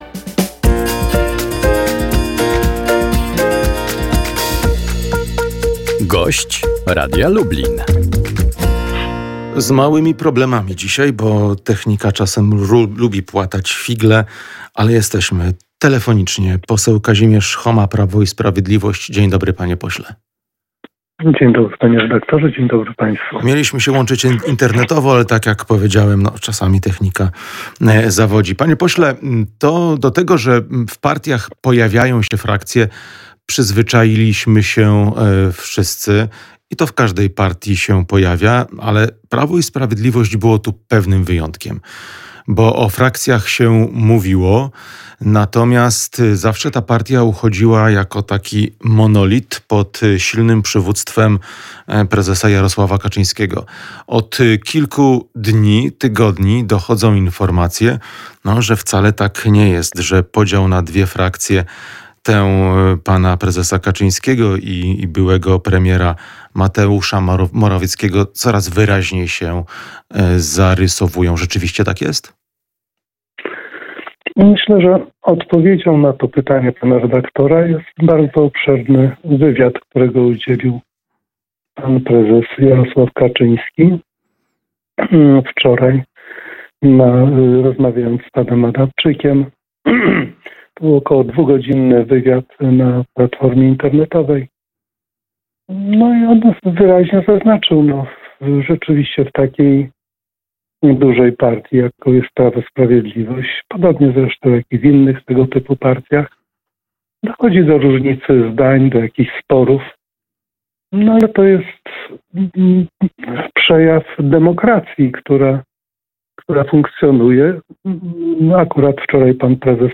O frakcjach w Prawie i Sprawiedliwości mówił gość Poranka z Radiem Lublin poseł PiS Kazimierz Choma.